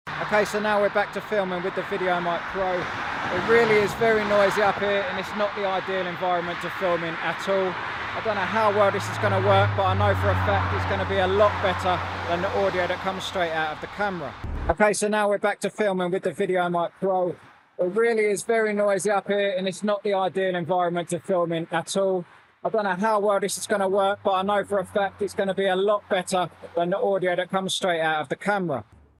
هندسة صوتية وازالة الضوضاء والتشويش وتنقية الصوت باحترافية 2